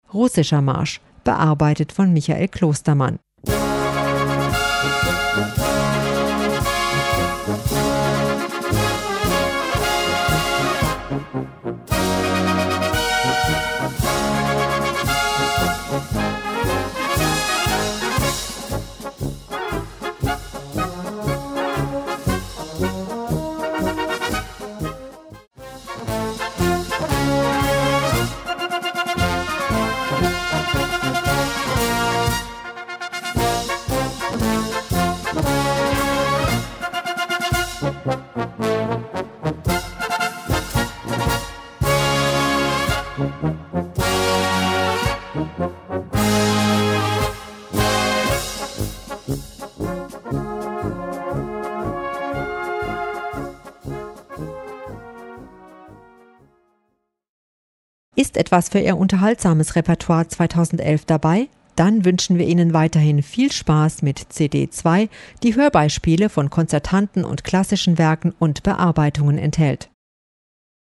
Komponist: Traditionell
Gattung: Marsch
Besetzung: Blasorchester